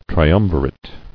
[tri·um·vi·rate]